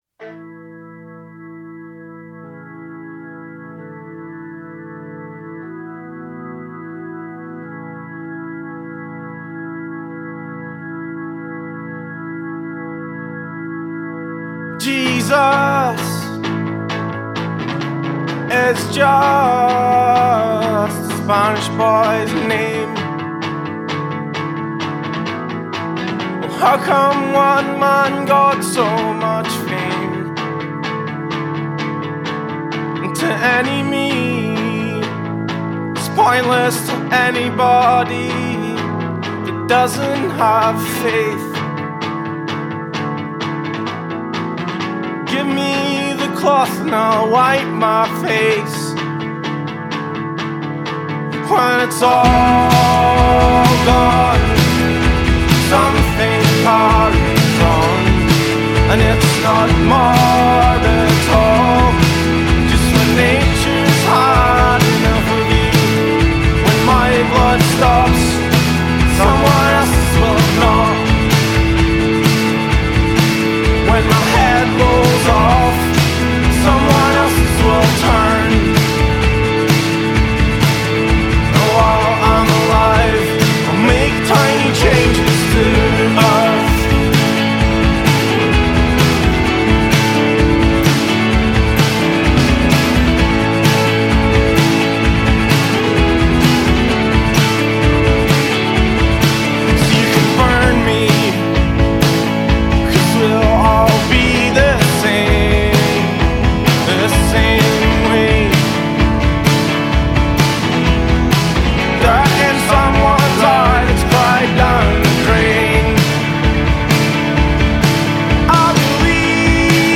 Genre: Rock
Style: Indie Rock/Folk Rock
wailing vocals